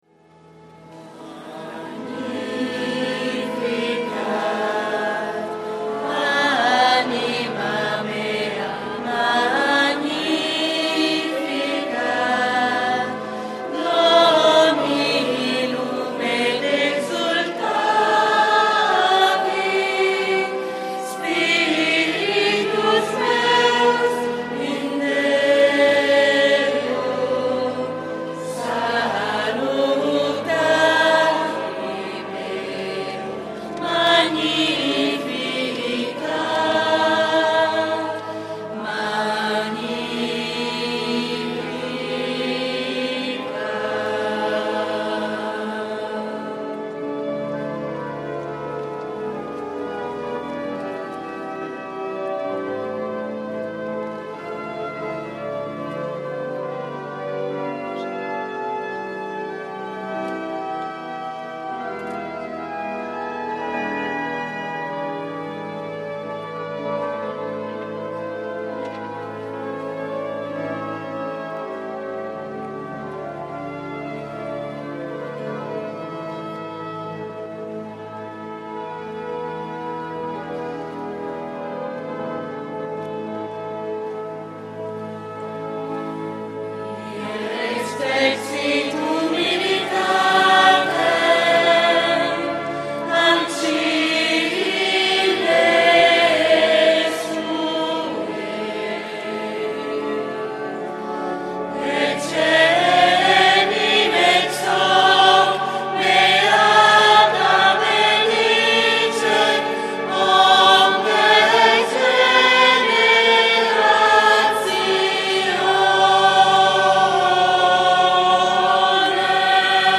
V domenica di Pasqua (Festa del matrimonio)